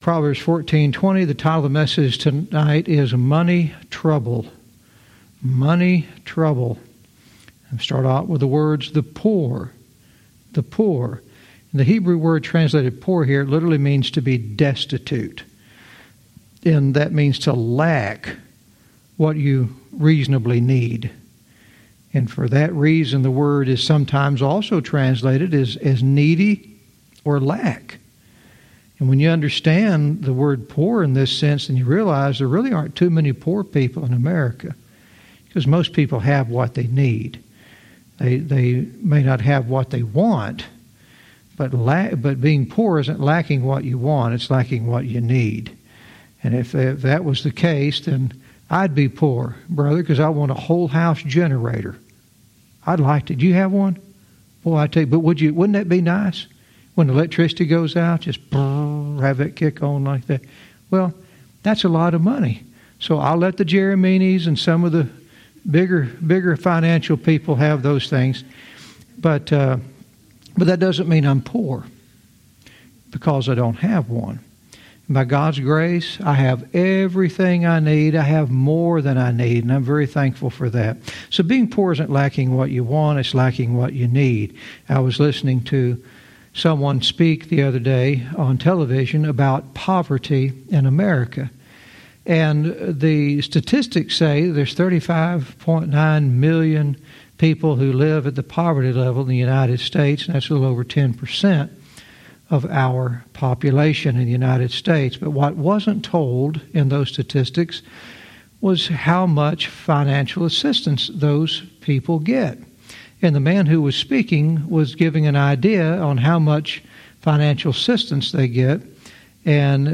Verse by verse teaching - Proverbs 14:20 "Money Trouble"